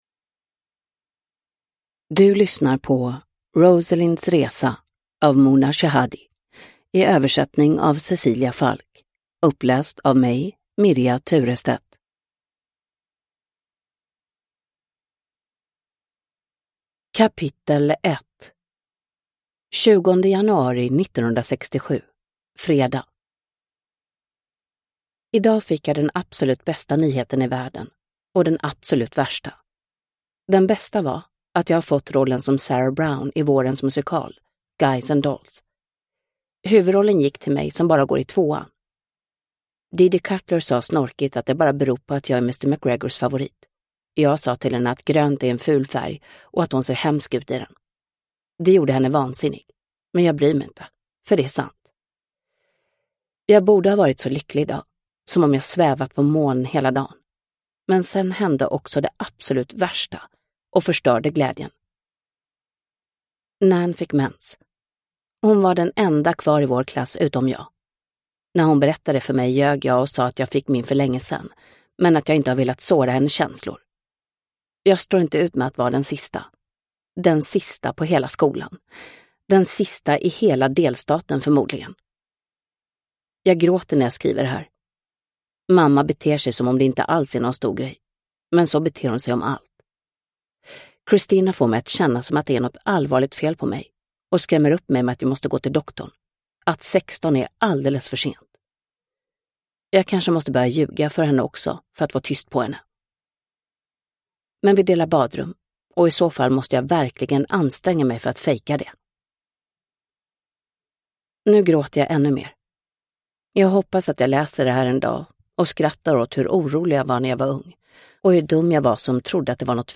Rosalinds resa (ljudbok) av Muna Shehadi